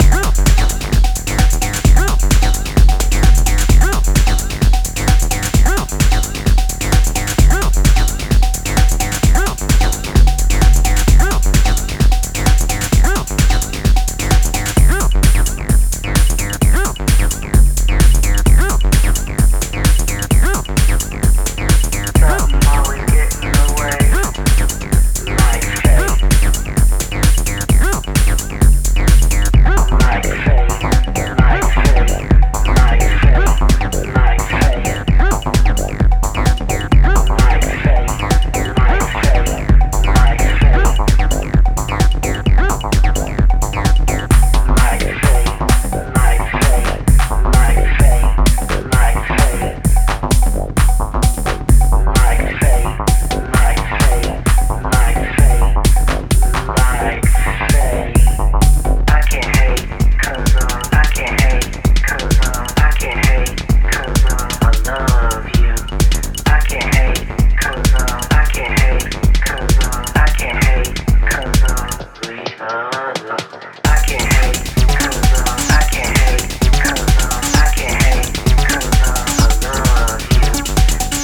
Driving electro tracks
solid acid infused